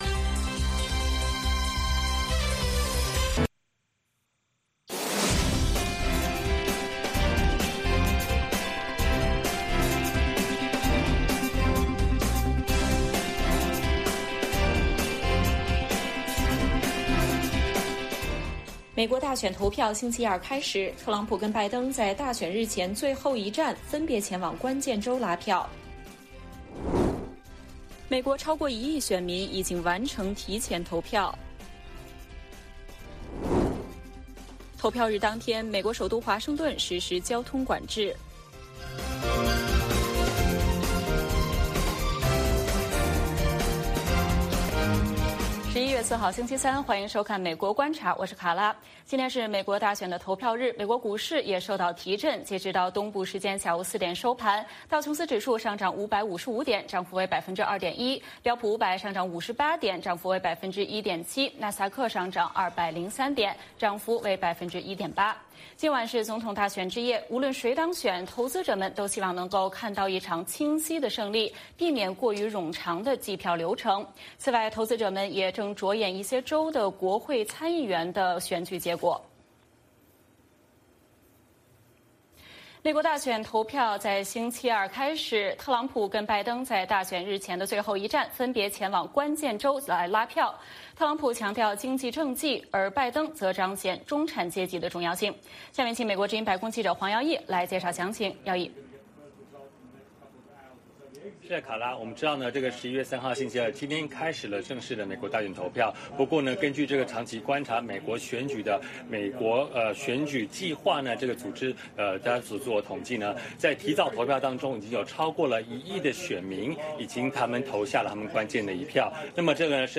美国观察(2020年11月4日)美国大选的投票日，目前已经有大约一亿名选民完成了提前投票，今天亲自前往投票站的选民也热情高涨。美国之音记者将从特朗普和拜登的竞选总部和全美多地发来现场报道。